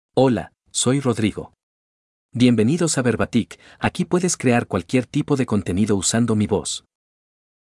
MaleSpanish (El Salvador)
Rodrigo is a male AI voice for Spanish (El Salvador).
Voice sample
Listen to Rodrigo's male Spanish voice.
Rodrigo delivers clear pronunciation with authentic El Salvador Spanish intonation, making your content sound professionally produced.